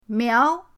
miao2.mp3